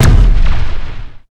plasma-turret-01.ogg